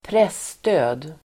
Uttal: [²pr'es:tö:d]